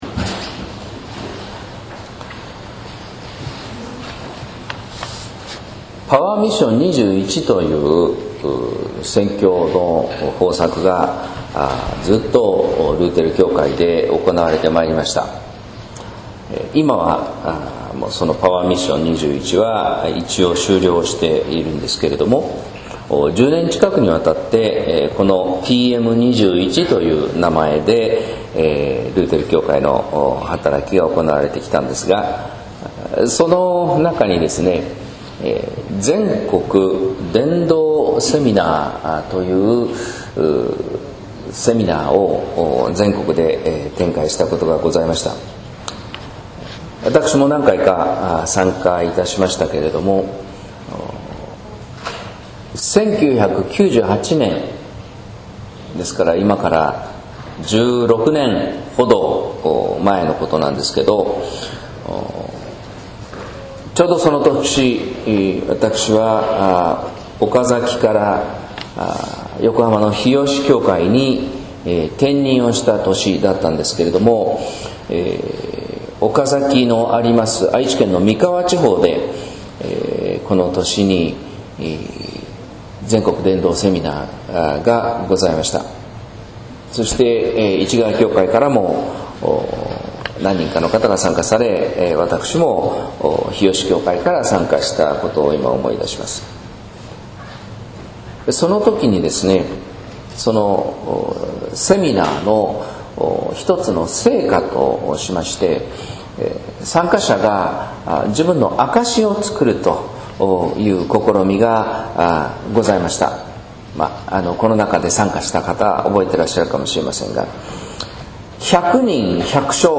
説教「しかし、イエスは言っている」（音声版）